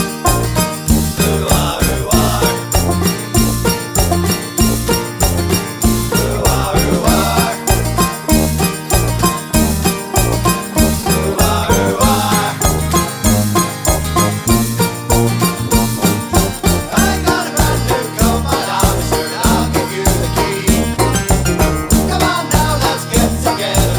no Backing Vocals Comedy/Novelty 3:02 Buy £1.50